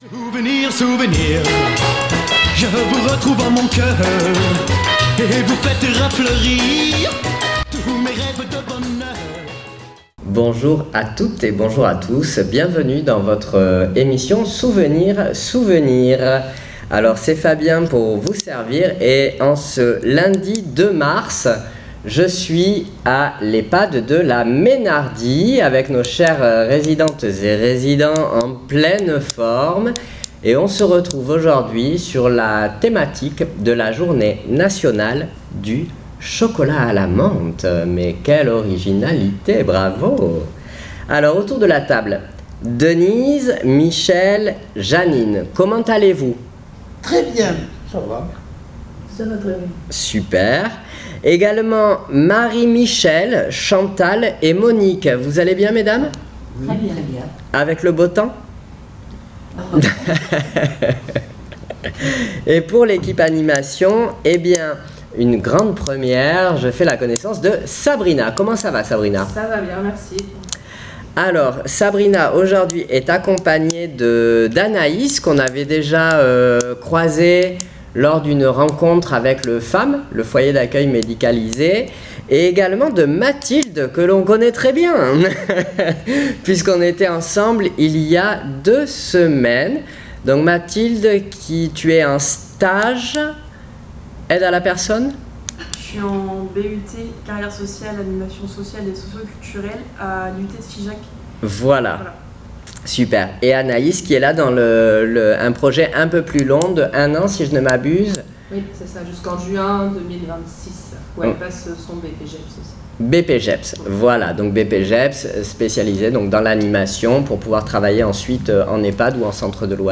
Souvenirs Souvenirs 02.03.26 à l'Ehpad de La Meynardie " Le chocolat à la menthe "